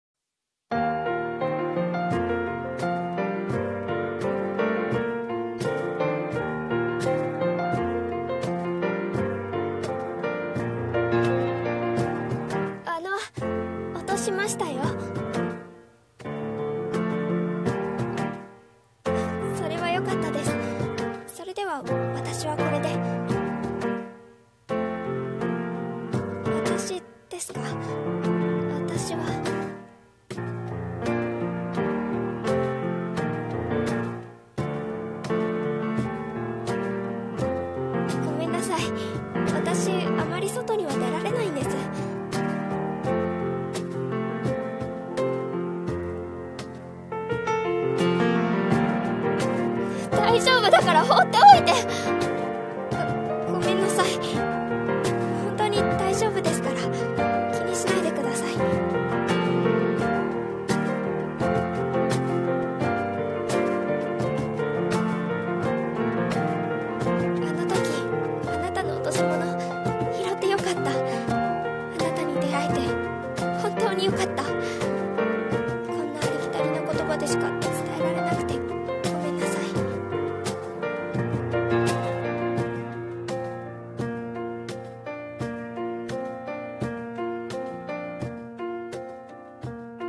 【声劇台本】夏に溺れた、僕らの話